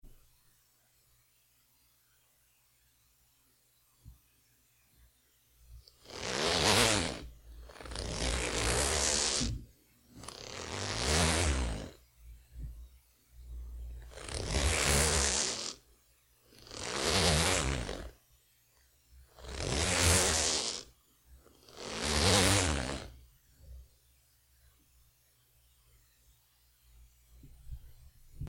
Cremallera
Una cremallera abriéndose y cerrándose.